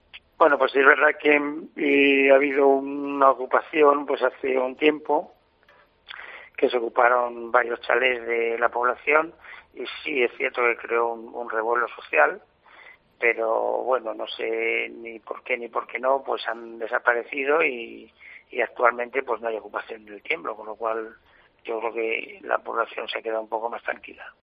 El caso más mediático, la ocupación de varias viviendas en El Tiemblo ya está resuelta, el alcalde ha contado en COPE Ávila que igual que llegaron se han ido
Arturo Varas, alcalde de El Tiemblo. Ocupación ilegal